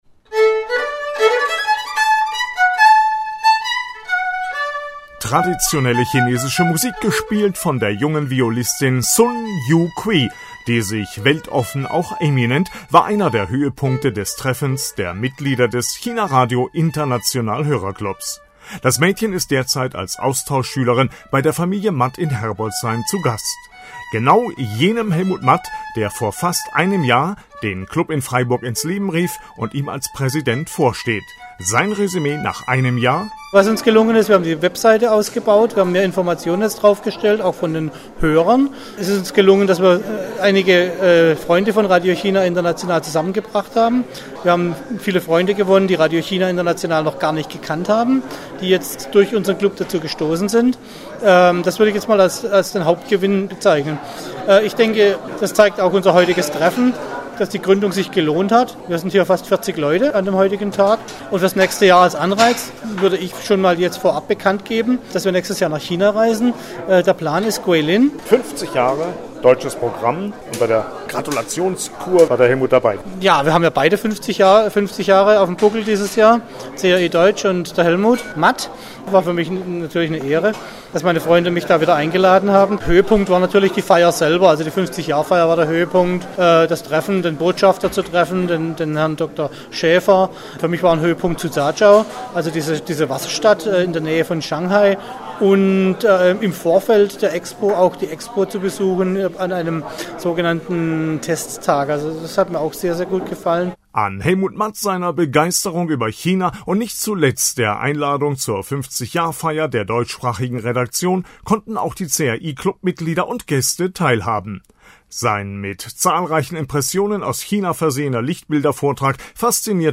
Hörbericht